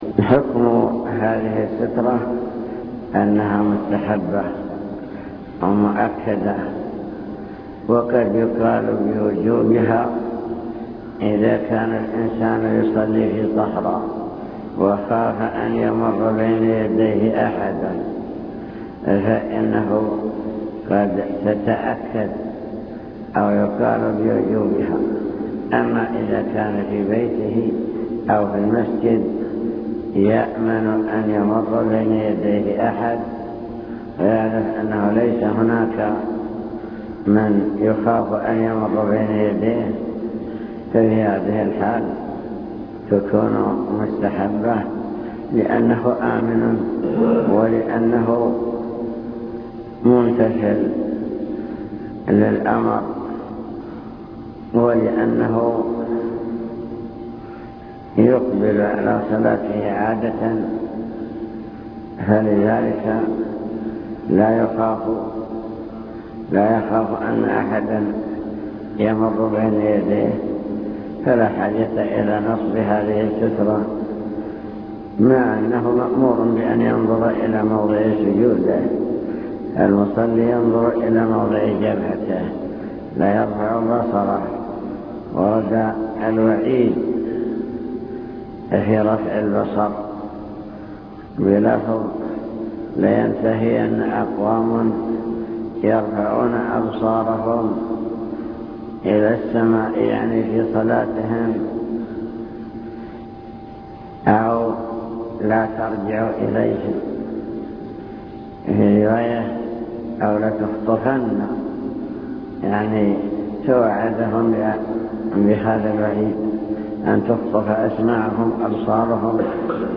المكتبة الصوتية  تسجيلات - محاضرات ودروس  درس في الجمعة مع بلوغ المرام سترة المصلي